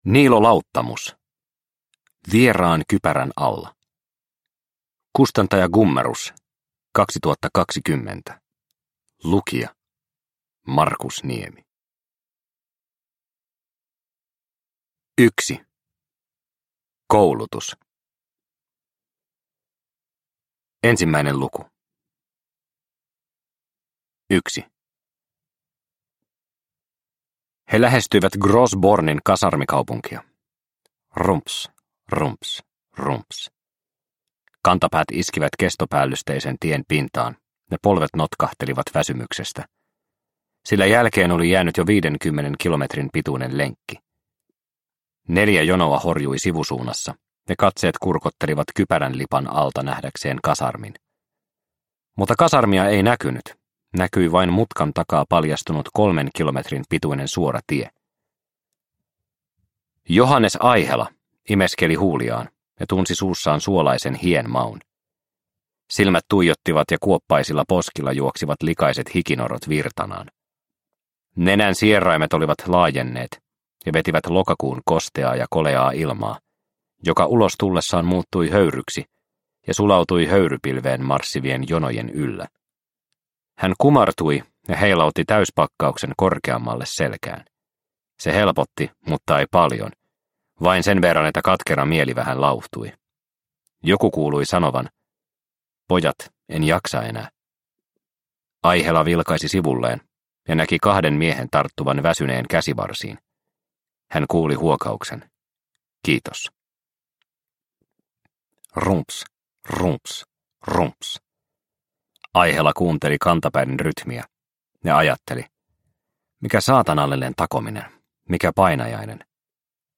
Vieraan kypärän alla – Ljudbok – Laddas ner